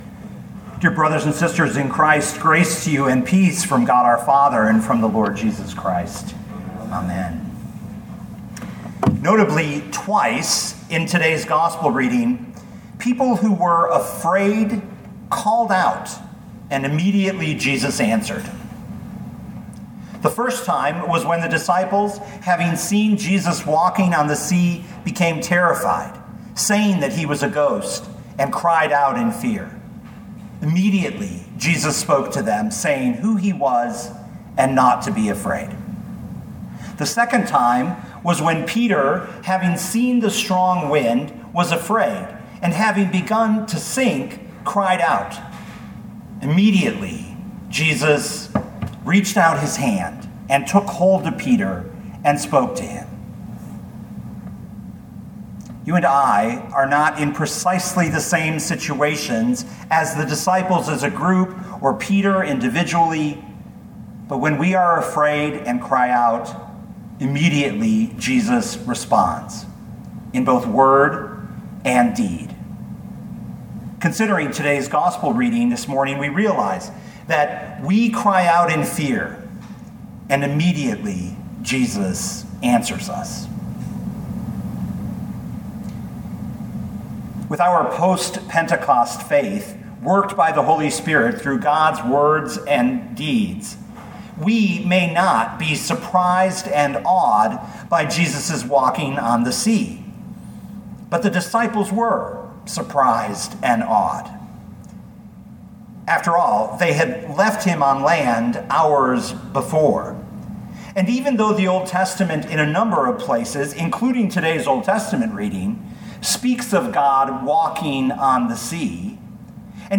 2020 Matthew 14:22-33 Listen to the sermon with the player below, or, download the audio.